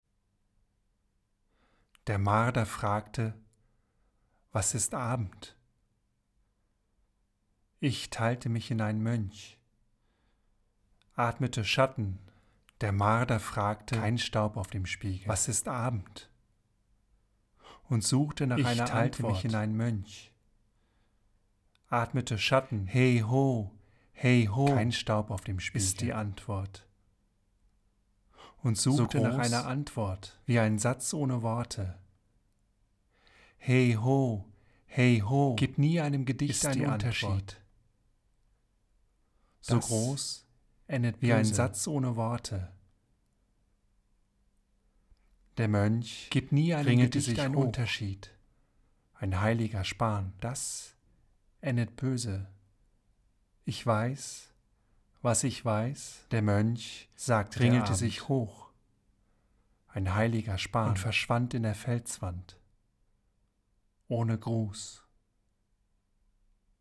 piano and voice